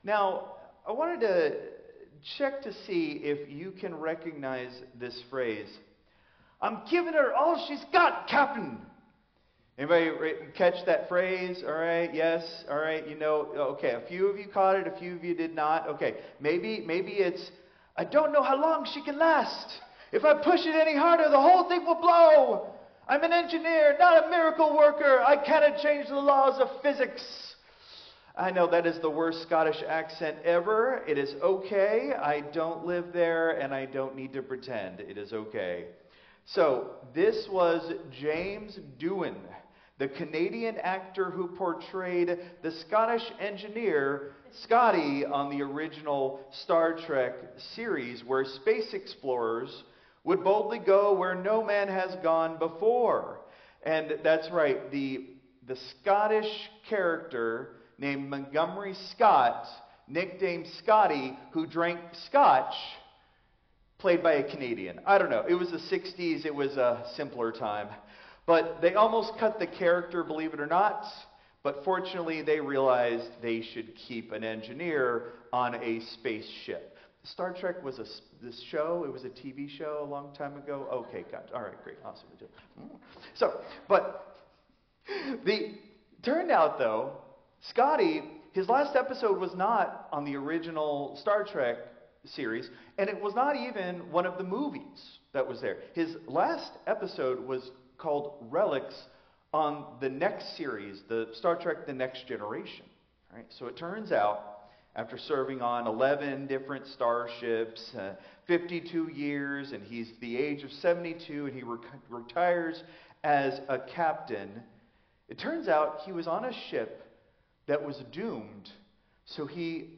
Christ Memorial Lutheran Church - Houston TX - CMLC 2025-03-02 Sermon (Traditional)